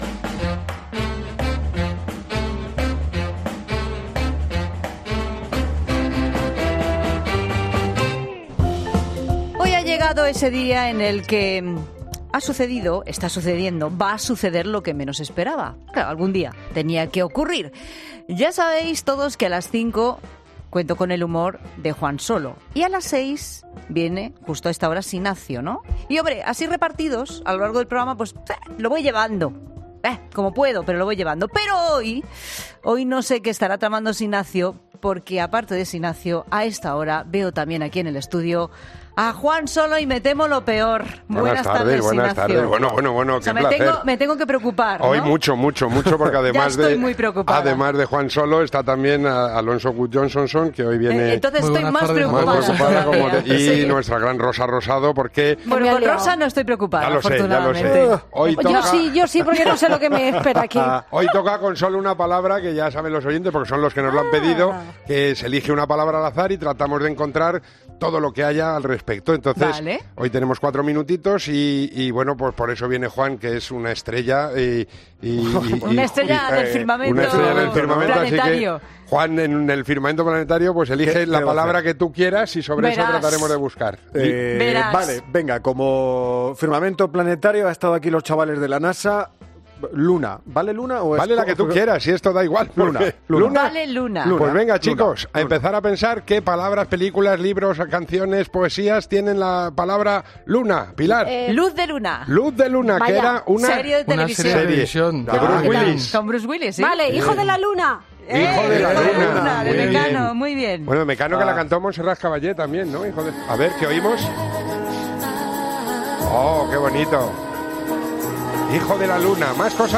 Hoy llenamos el estudio de la Tarde de Cope de Luz de Luna y estrellas.